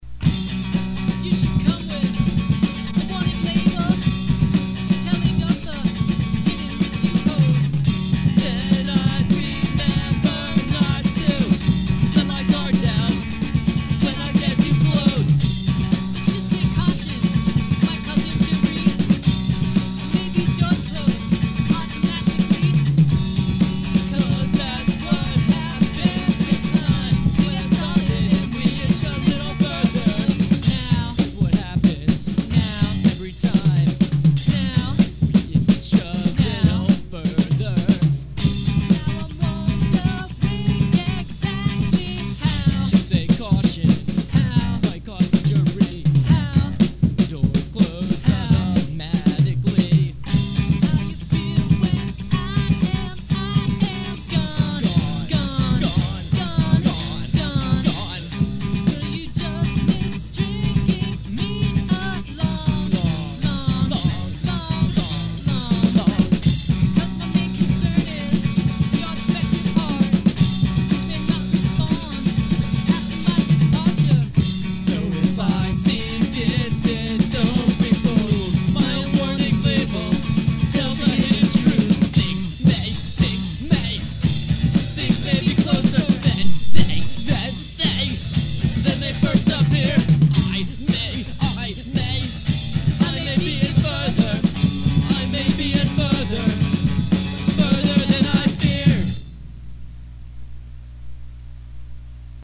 bass, me on drums, and both of us singing.